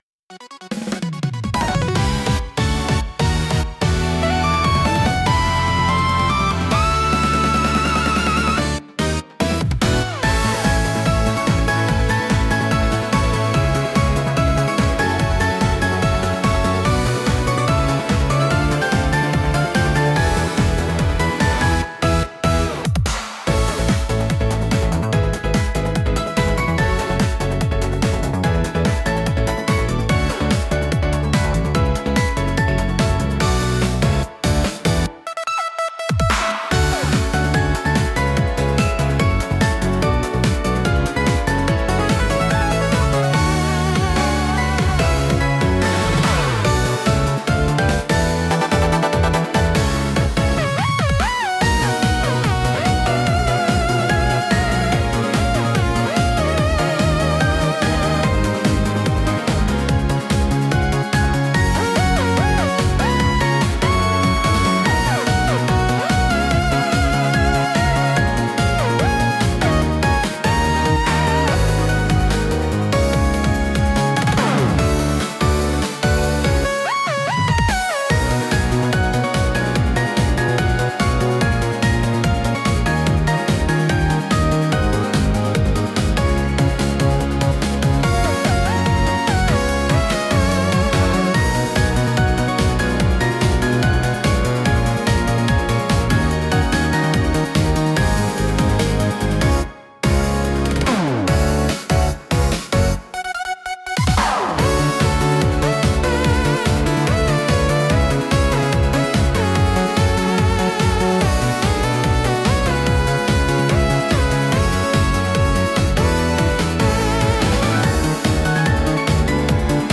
聴く人の気分を高め、緊張と興奮を引き立てるダイナミックなジャンルです。